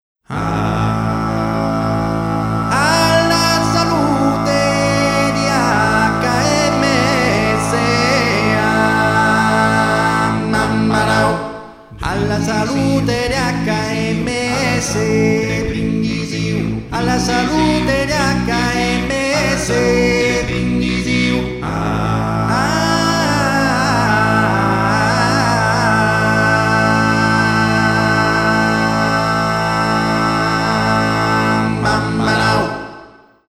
Sarda